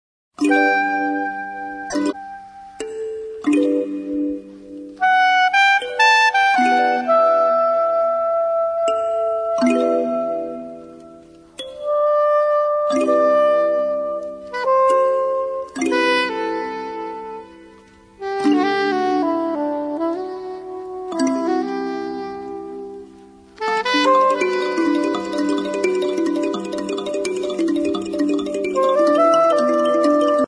The CD has 14 compositions,mostly vocal songs.